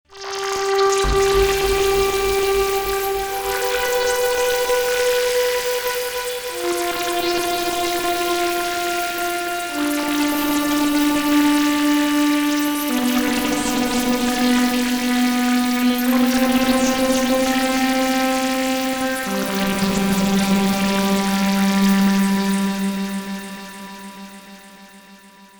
Elektron Gear Analog Keys/Four
Some recent experiments (AK)…